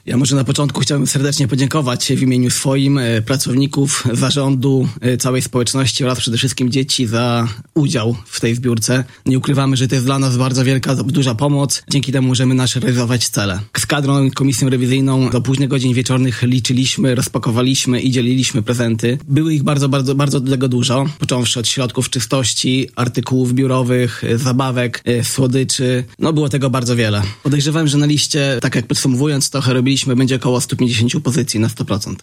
w Studiu Radia 5